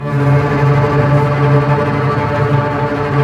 Index of /90_sSampleCDs/Roland LCDP08 Symphony Orchestra/STR_Cbs Bow FX/STR_Cbs Tremolo